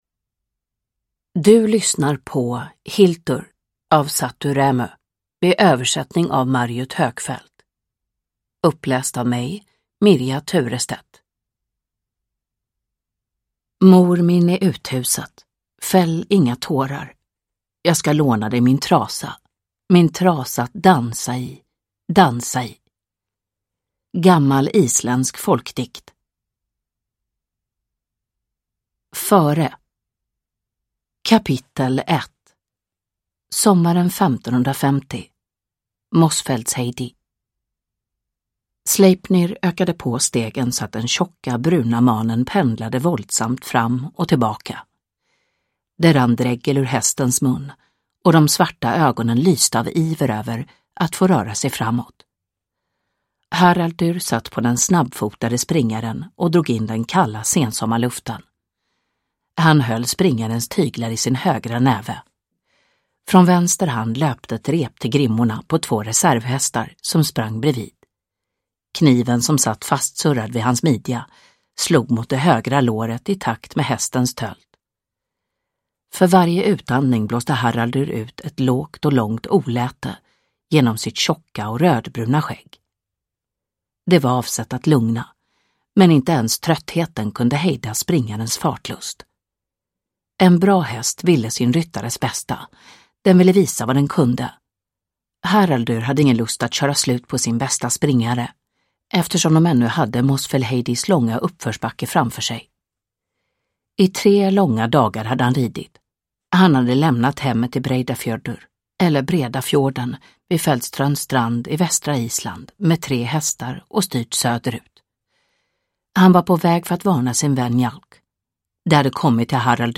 Downloadable Audiobook
Ljudbok